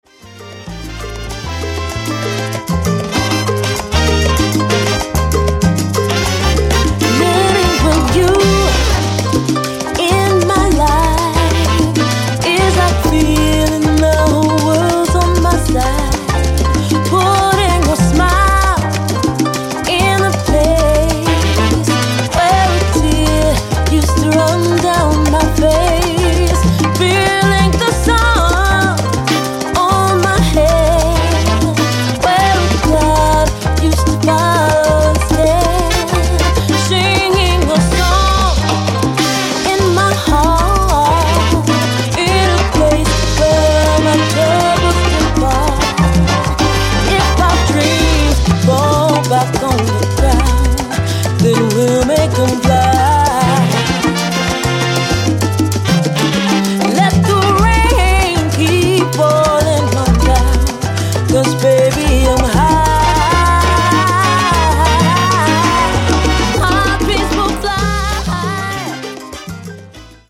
ベーシスト
ジャンル(スタイル) LATIN / SALSA